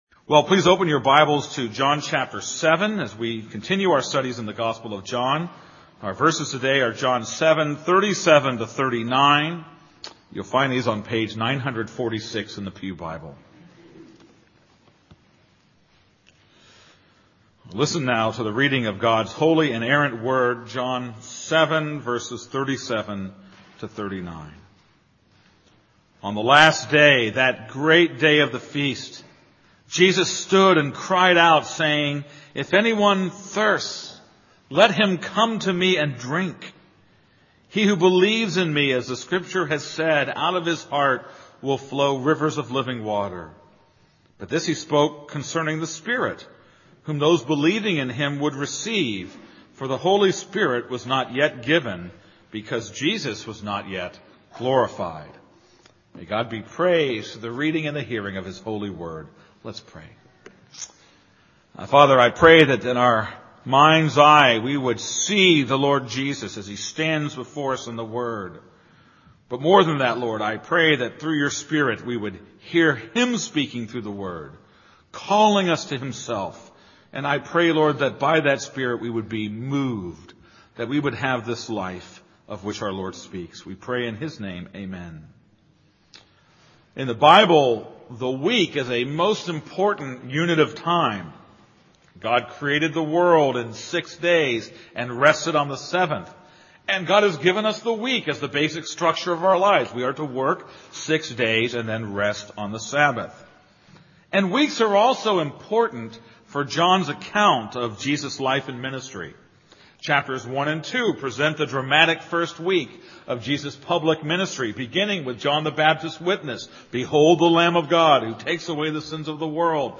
This is a sermon on John 7:37-39.